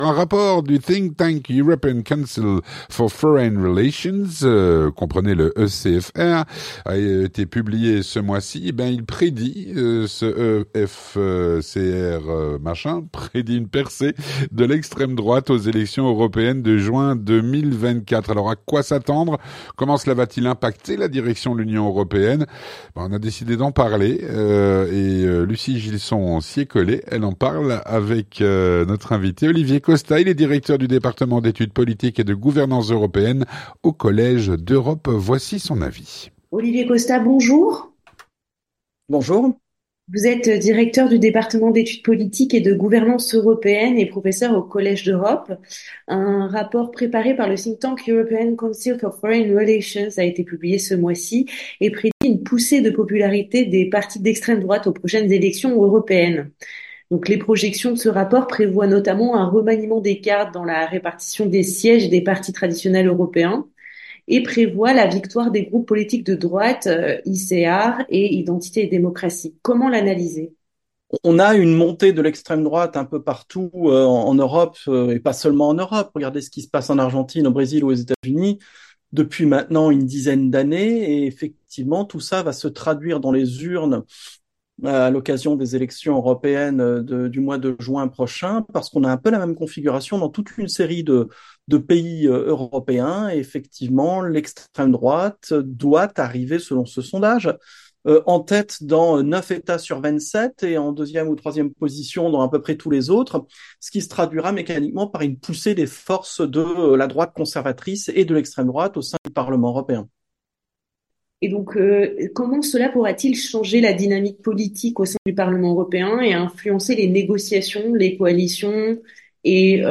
L'entretien du 18H - Un rapport publié ce mois-ci prédit une percée de l’extrême droite aux élections européennes de Juin 2024.